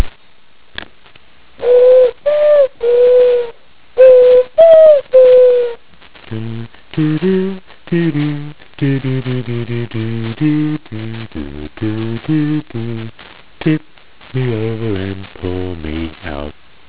Walking around the island's deserted roads, the only sound I usually here is the Jeopardy birds (they're not really called that - in fact I have no idea what they're called or even what they look like. I just call them that because the make a sound that reminds me of the beginning of the famous jingle on the TV quiz show.
Jeopardy_bird.wav